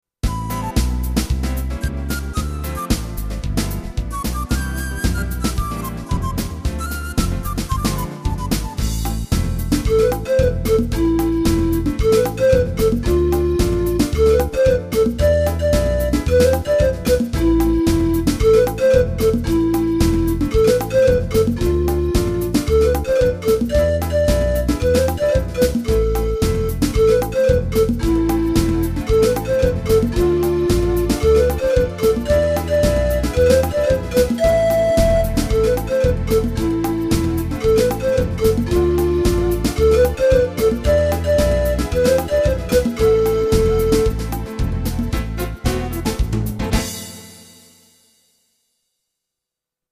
Arrangiamenti didattici di brani d'autore